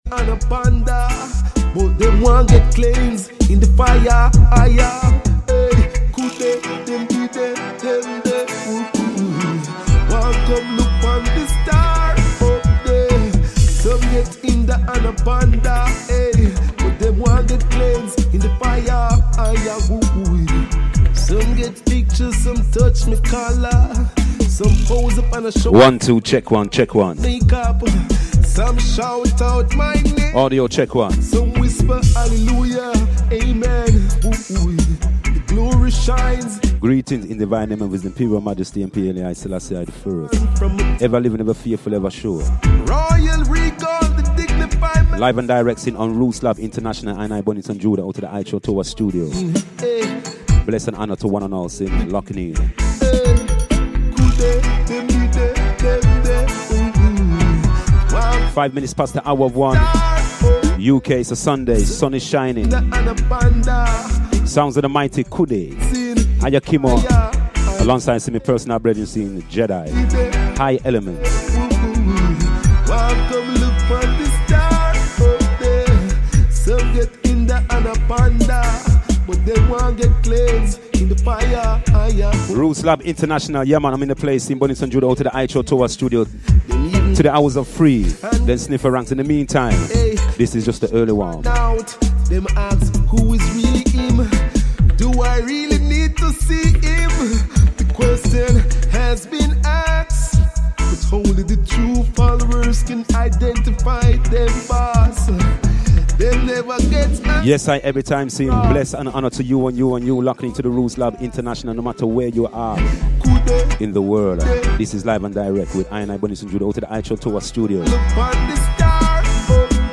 3PM UK STEPPIN UP DUB SUNDAYS http